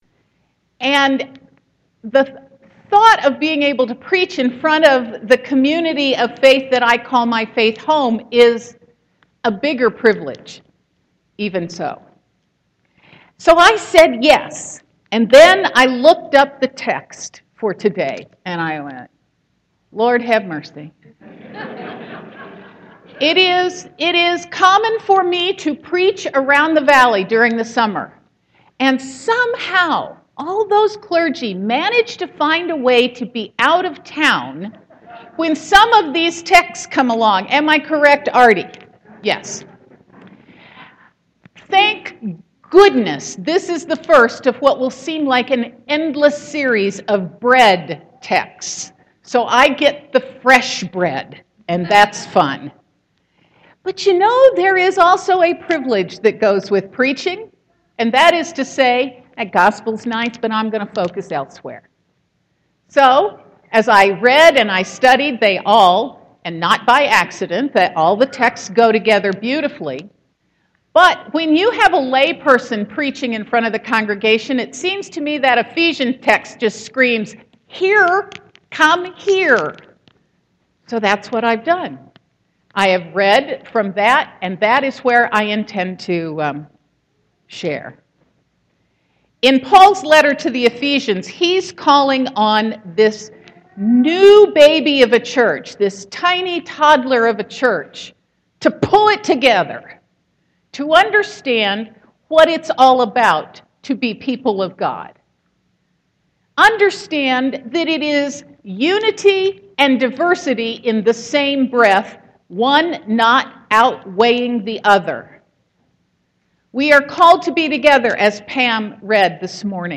Sermon 8.2.2015 -